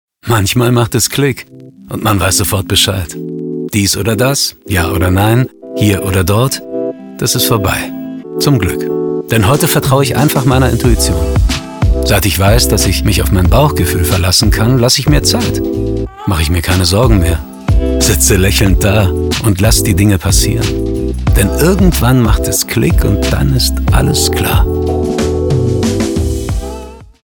Radiowerbung: Funkspot bundesweit Radioplayer